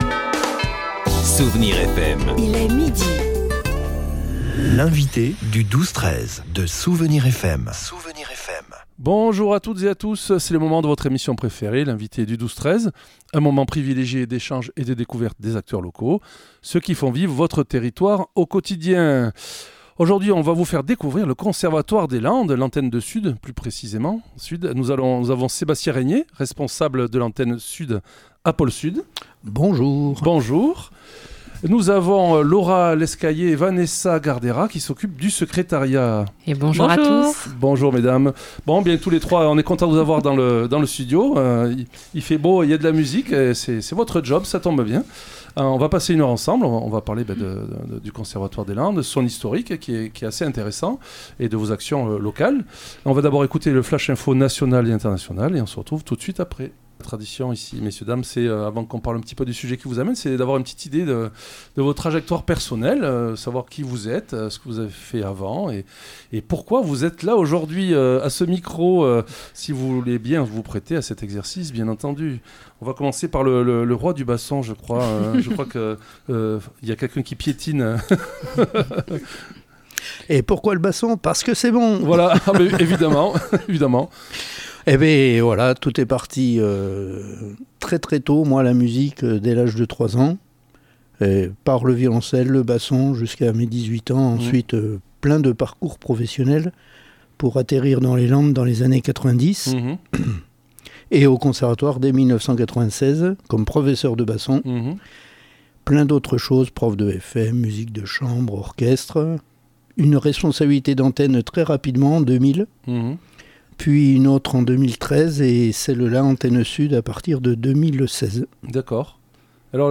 L'invité(e) du 12-13 de Soustons recevait aujourd'hui Le conservatoire des Landes, l’antenne SUD pour être plus précis.